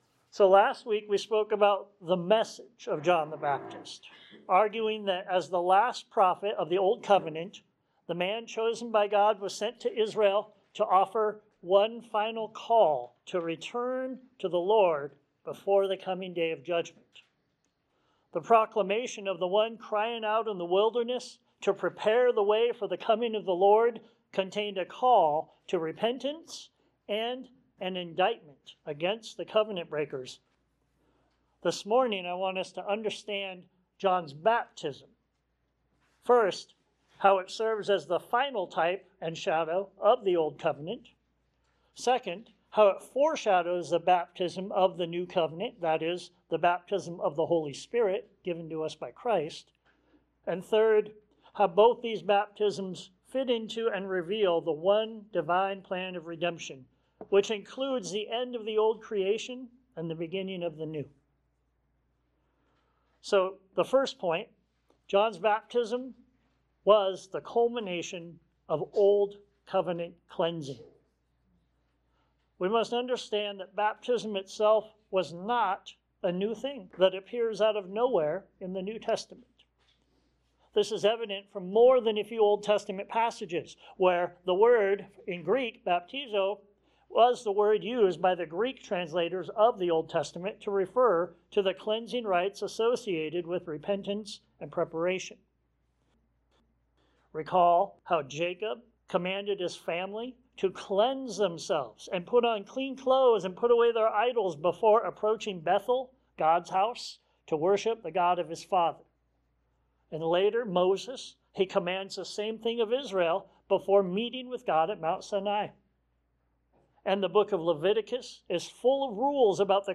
Baptism: Then & Now John 29-34 Sermons Share this: Share on X (Opens in new window) X Share on Facebook (Opens in new window) Facebook Like Loading...